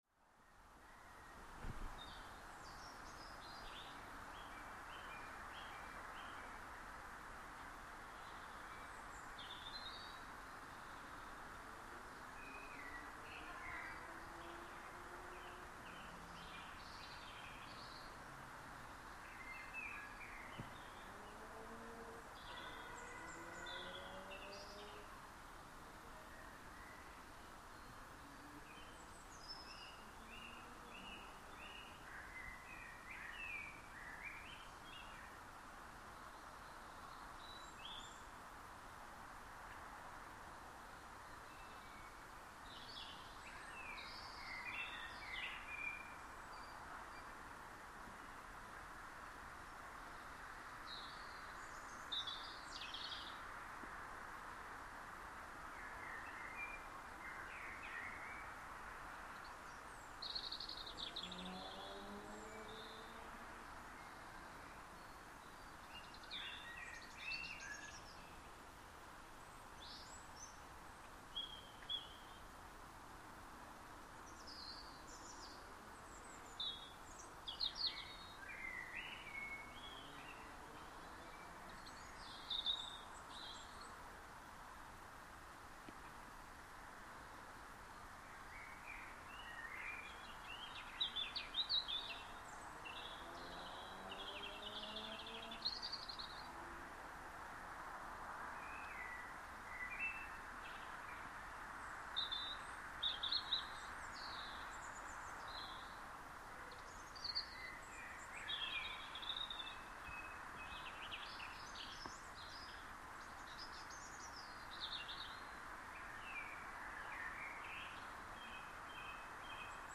Malvern Birds at Dusk, recorded earlier this evening. I love the way you can hear where different birds are with stereo.